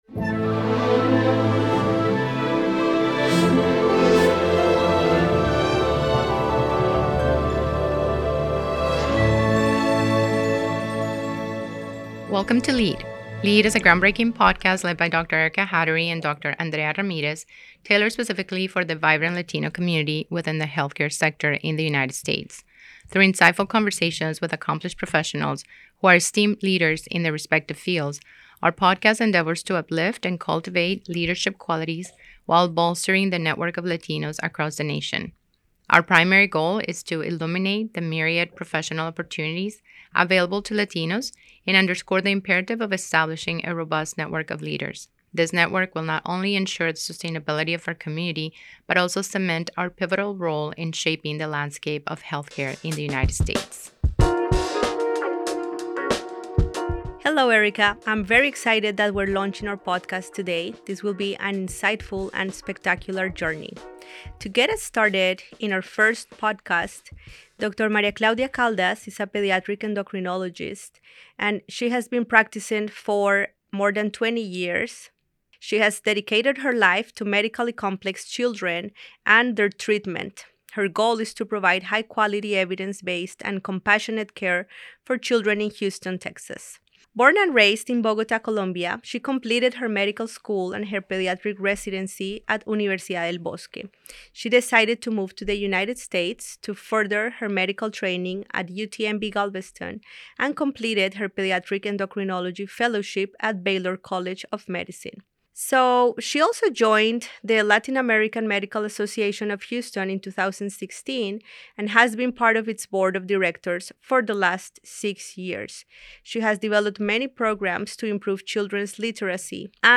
Through candid conversations with physicians and healthcare leaders, we explore stories of resilience, innovation, and impact — celebrating Latino excellence and inspiring thought leadership within our community. Each episode offers insights into the hard work, vision, and transformative ideas shaping the future of American healthcare.